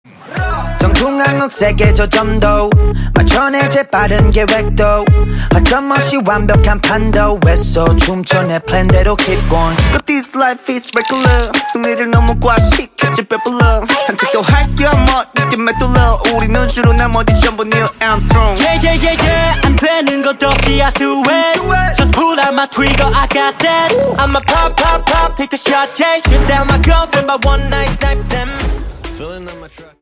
KPop